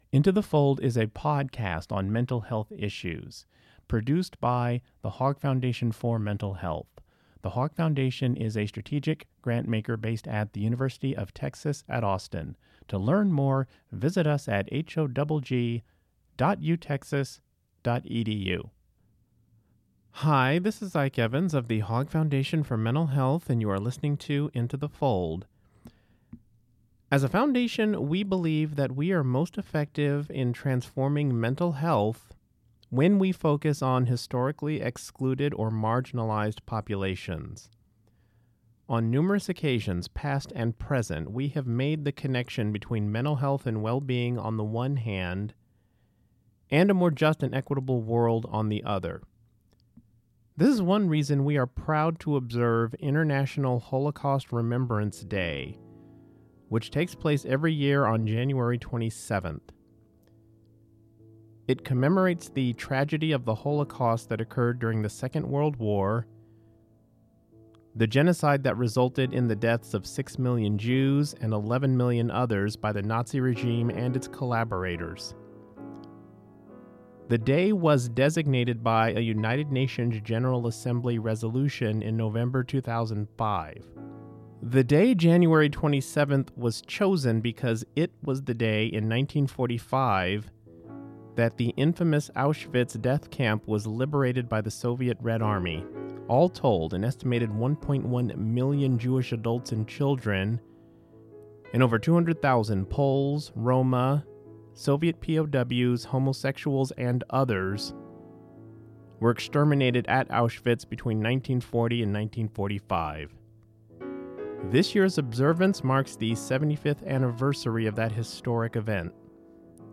In observance of International Holocaust Remembrance Day on January 27th, this episode of Into the Fold features an interview from a late 1970s episode of the Human Condition, a radio series produced by the Hogg Foundation between 1972 and 1983.